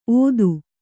♪ ōdu